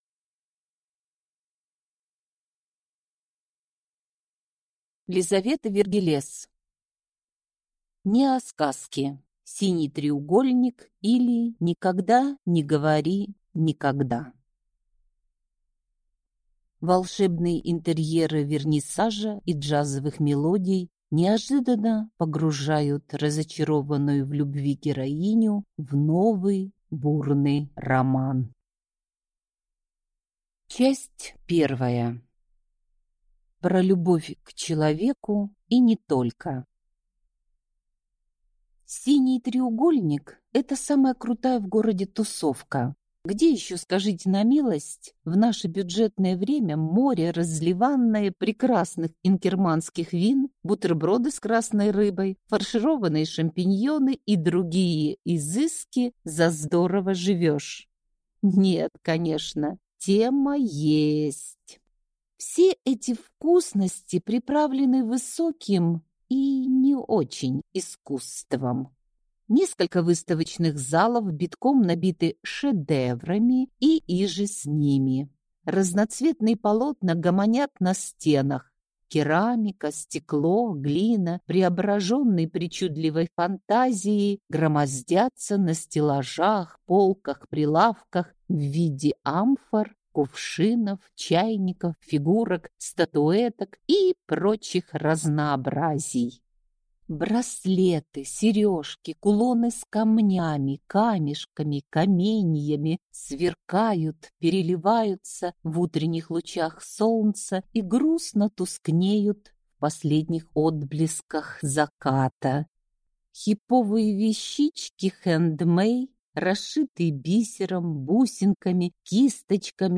Аудиокнига Неосказки. Синий треугольник, или Никогда не говори «никогда» | Библиотека аудиокниг